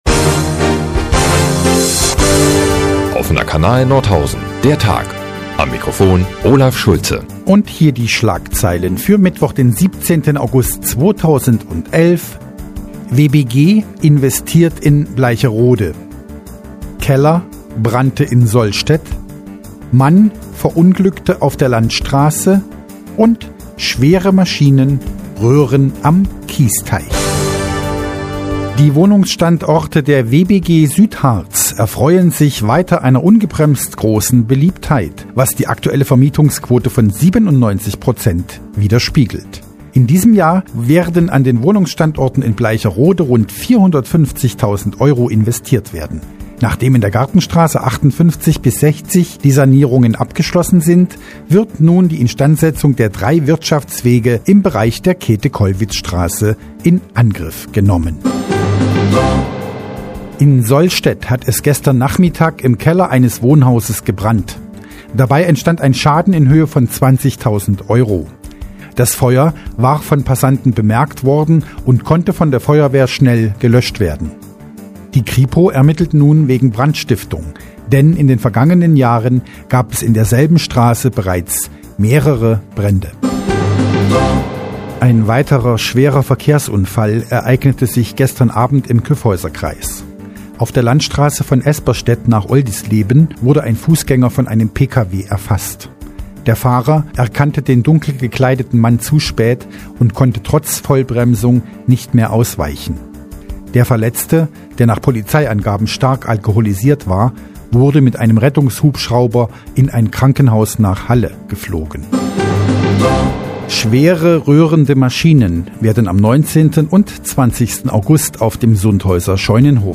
Die tägliche Nachrichtensendung des OKN ist nun auch in der nnz zu hören. Heute mit einem Kellerbrand, einem betrunkenen Fußgänger, einer Bikeshow und WBG-news.